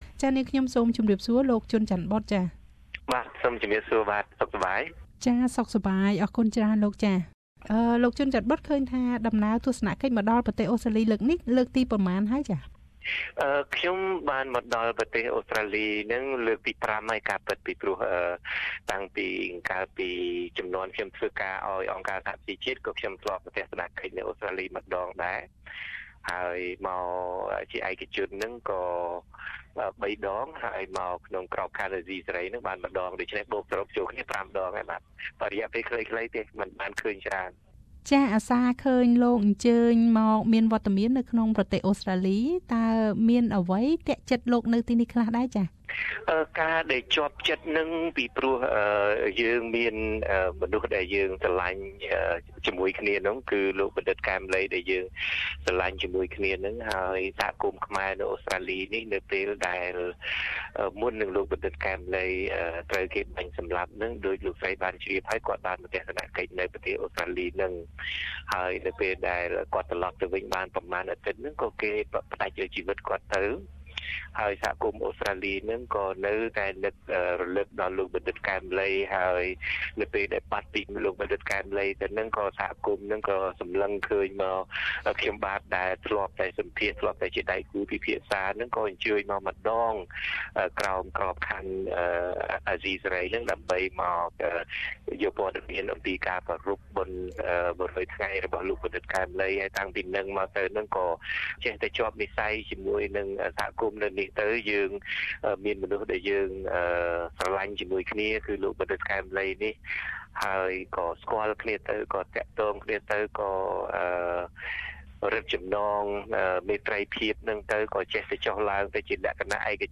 តើជាដំណើរទស្សនកិច្ចផ្ទាល់ខ្លួនឬផ្លូវការ? សូមស្តាប់នូវបទសម្ភាសន៍ដូចតទៅ។